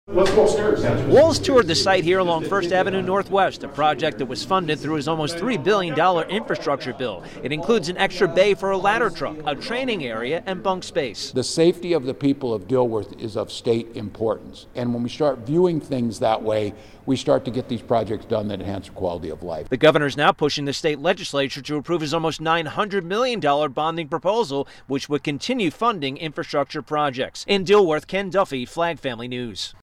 reports from Dilworth